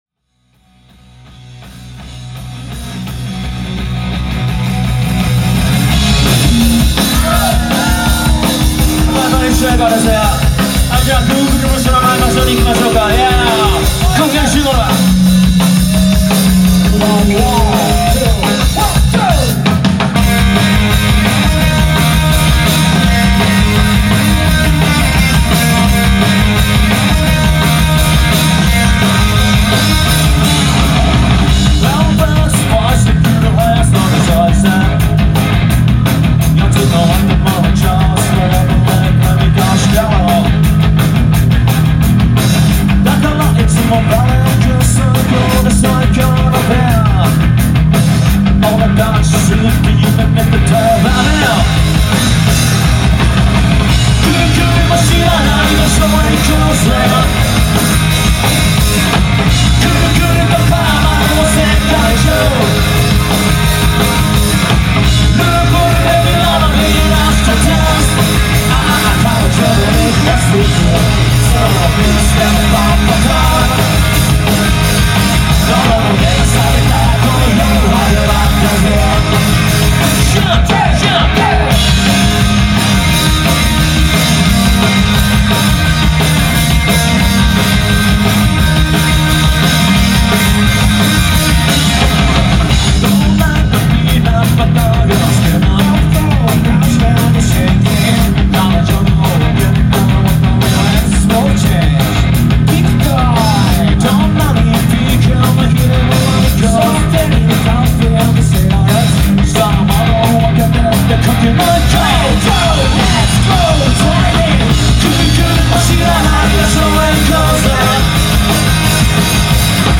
※収録曲数：20曲収録 (ライヴ盤として聴いてもらいたいので、MCは極力カットしております）
※試聴音源